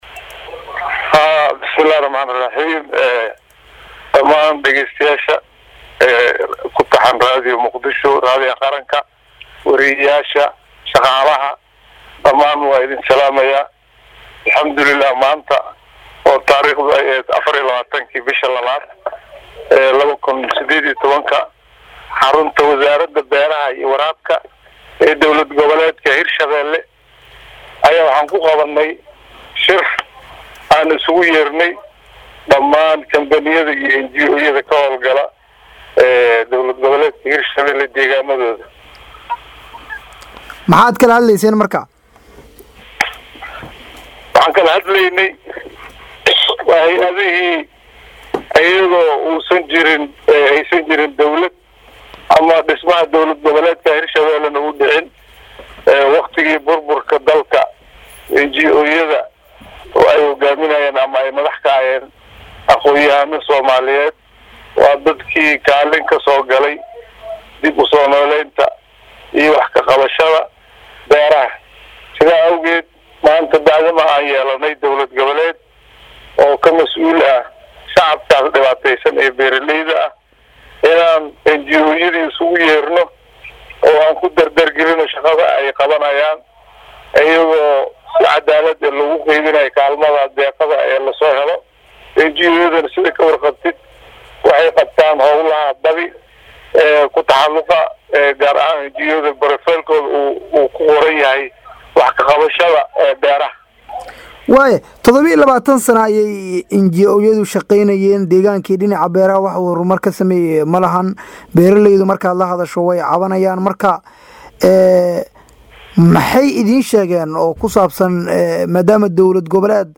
Wasiirka Wasaaradda Beerlaha dowlad goboleedka Soomaaliyeed ee Hirshabeelle Xasan Axmed Muudeey oo la hadlay Radio Muqdisho Codka Jamhuuriyadda Soomaaliya ayaa faah